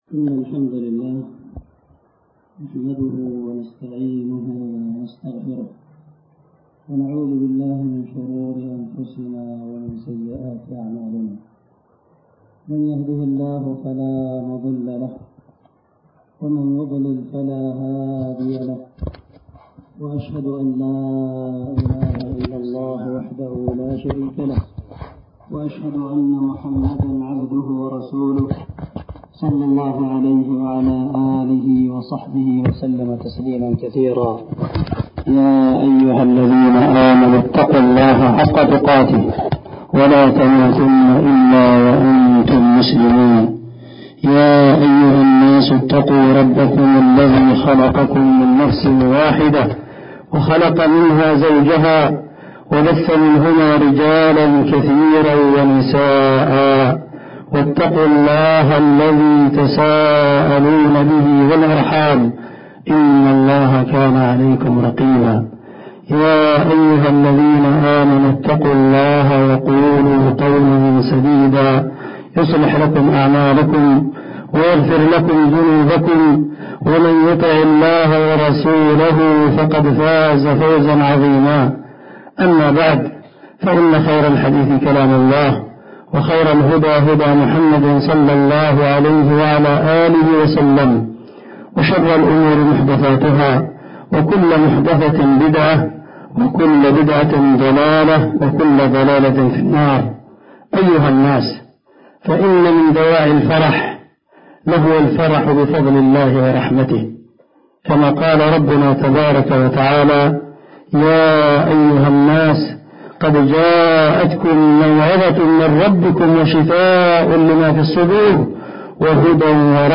خطبة عيد الفطر المبارك